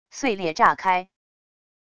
碎裂炸开wav音频